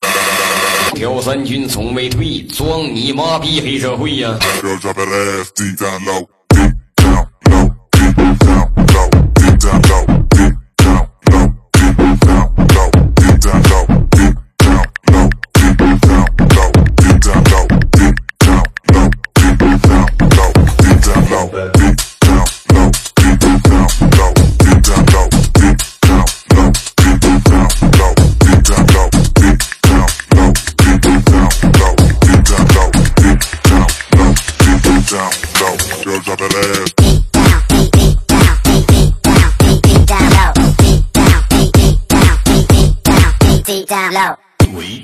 本铃声大小为260.3KB，总时长45秒，属于DJ分类。